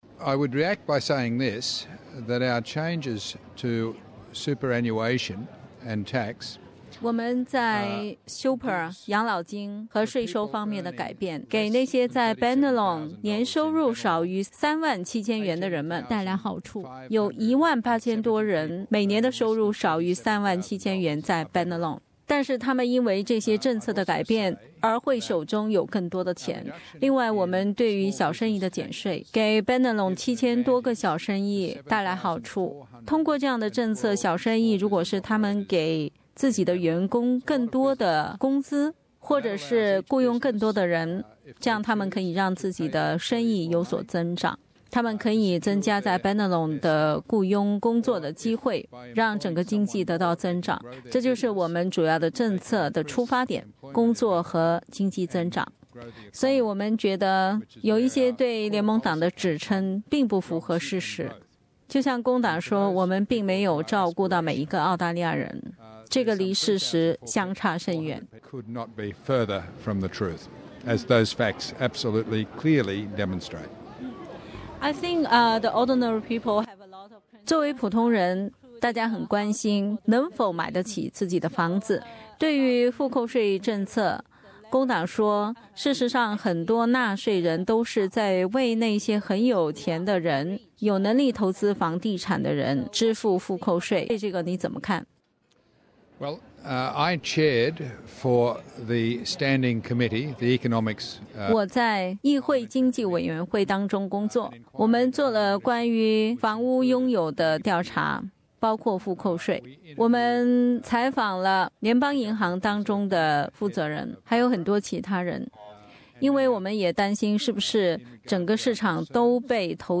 SBS普通話和廣東話節目6月23日在Chatswood舉辦大選戶外廣播，對Bennelong等選區的各黨派和獨立葠選人，以及政界人士所作的系列寀訪。